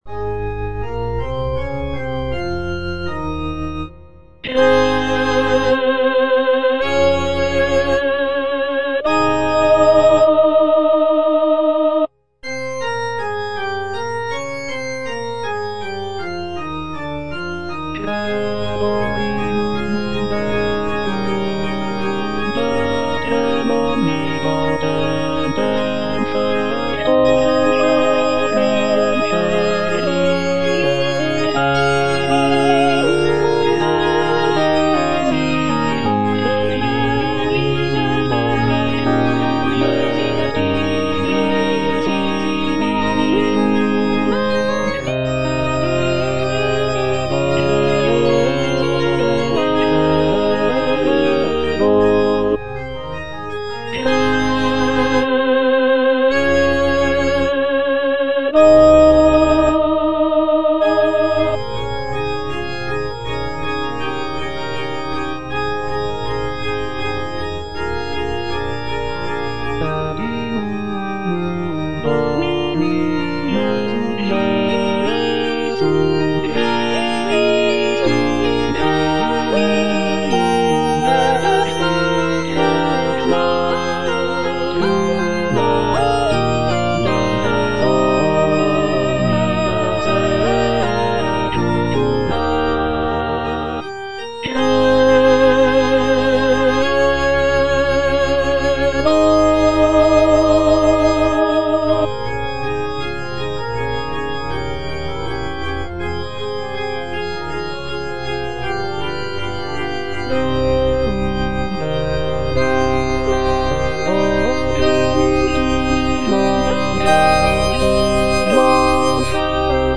C.M. VON WEBER - MISSA SANCTA NO.1 Credo (All voices) Ads stop: auto-stop
"Missa sancta no. 1" by Carl Maria von Weber is a sacred choral work composed in 1818.
The work features a grand and powerful sound, with rich harmonies and expressive melodies.